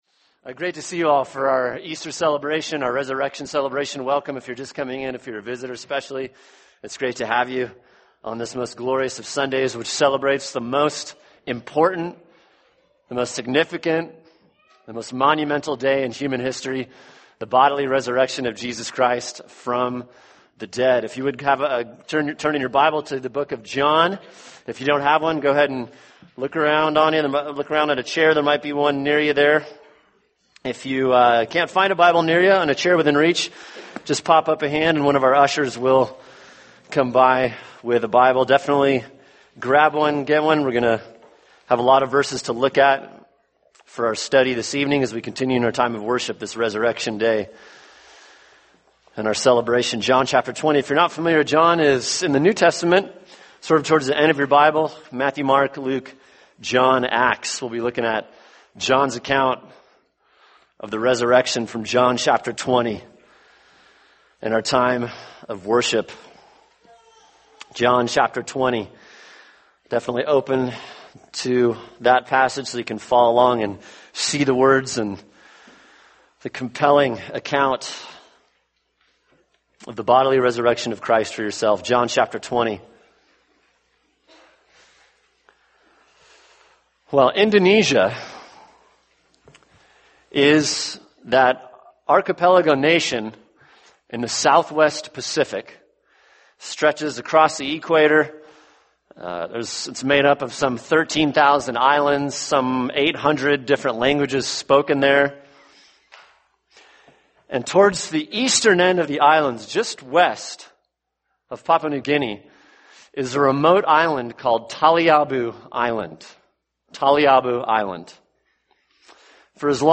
[sermon] John 20 – Resurrection | Cornerstone Church - Jackson Hole